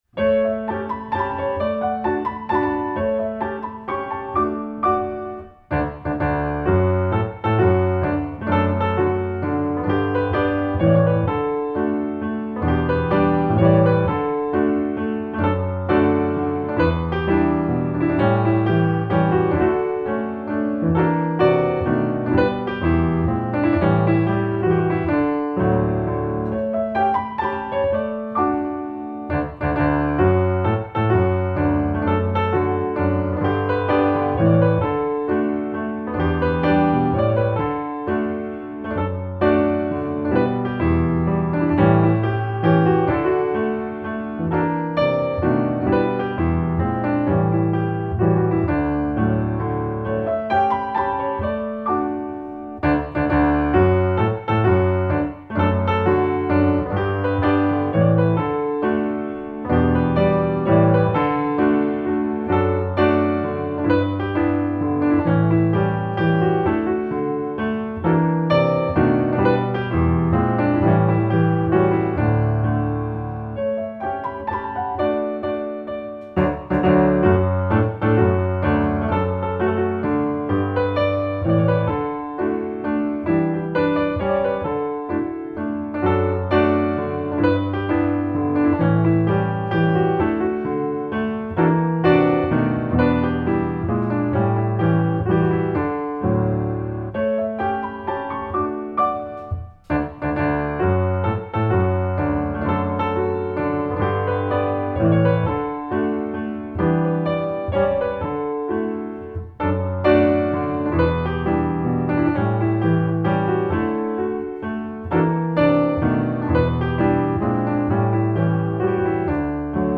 na motywach melodii ludowej
akompaniament